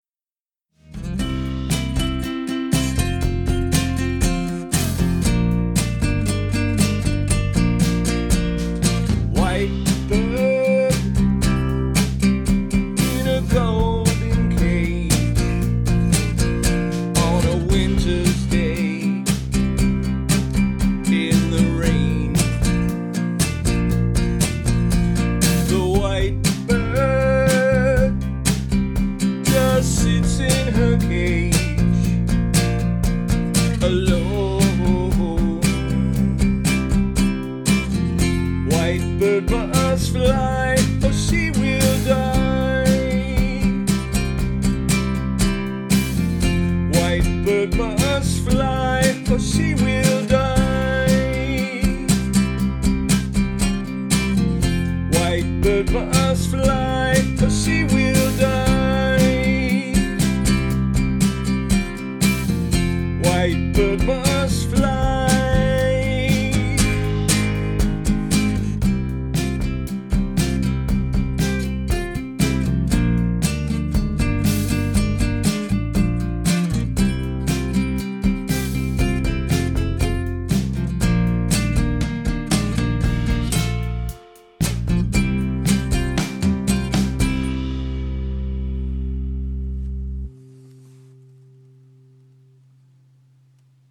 Genre: Classic Rock.